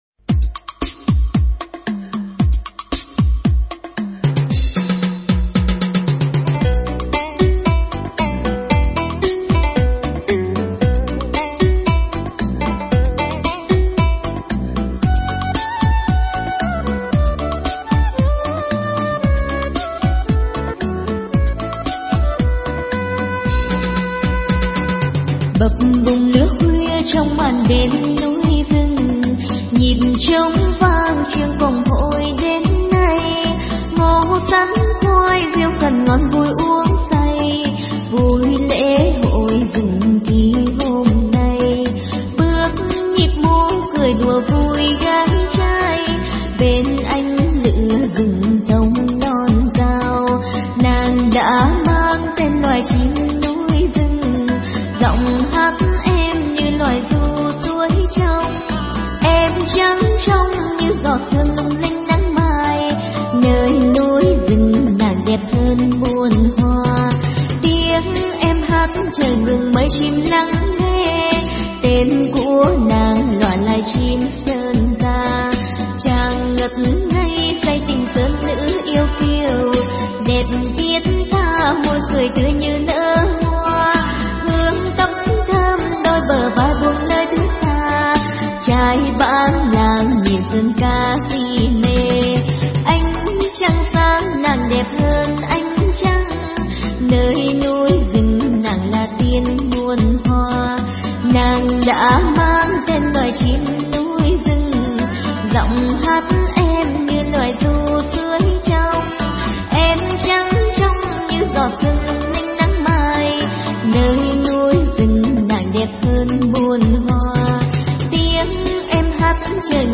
Nhạc Dân Tộc Remix.